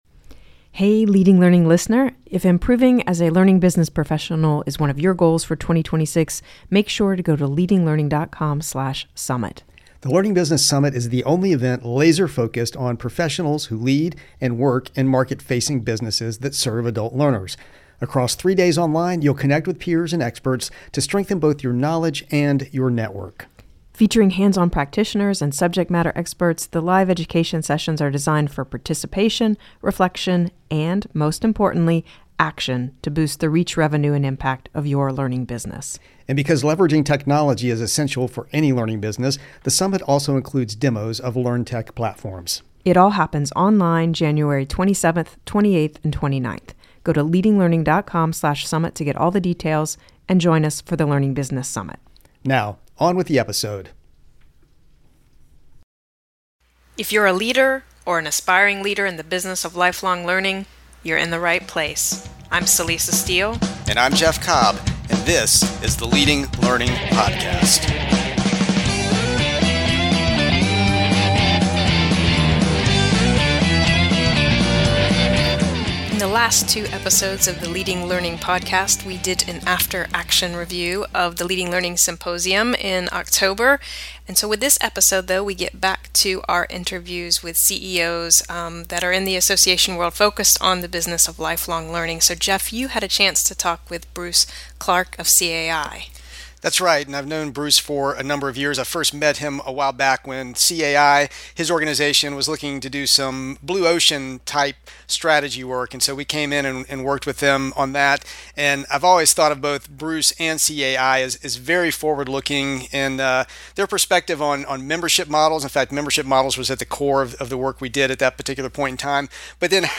If you have been listening to the Leading Learning podcast for a while, you know that one of our ongoing focus areas is interviews with top executives at membership organizations to get their perspectives on the role of learning in providing value to members and customers.